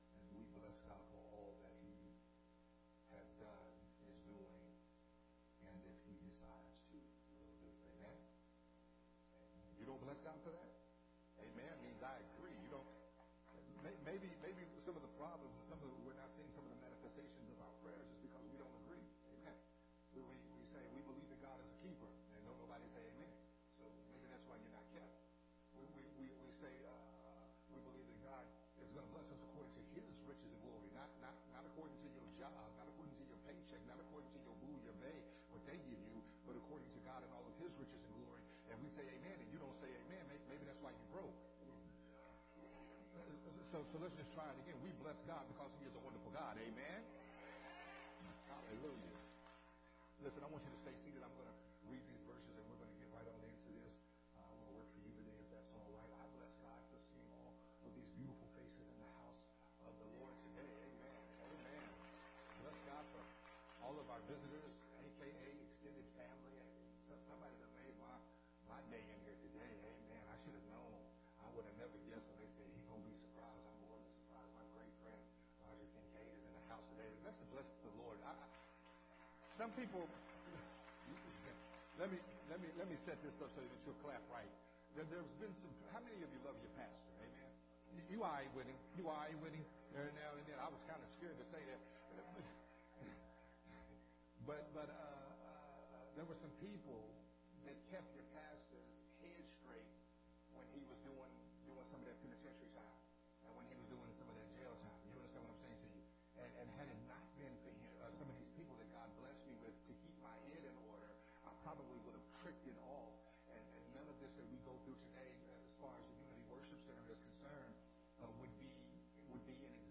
sermon
recorded at Unity Worship Center